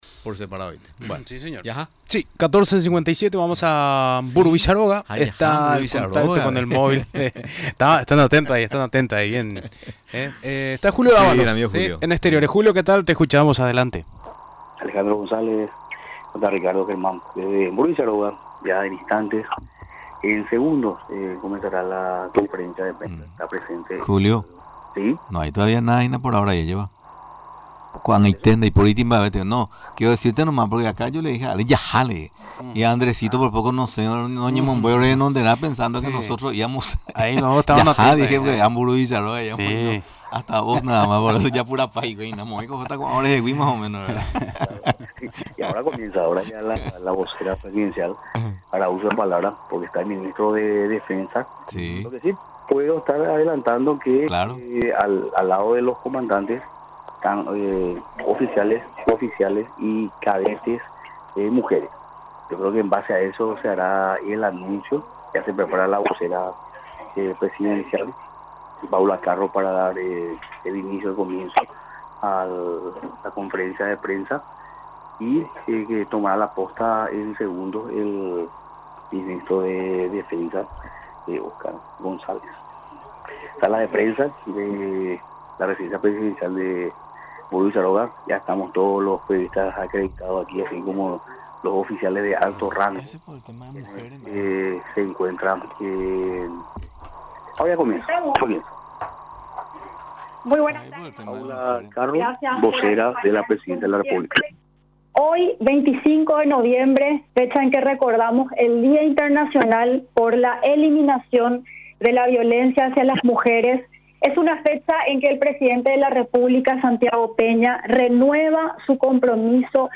Durante la conferencia de prensa, realizada en la Residencia Presidencial de Mburuvichá Róga, el ministro de Defensa Nacional, explicó que la autorización se basa en argumentos donde se destaca el patriotismo histórico de la mujer.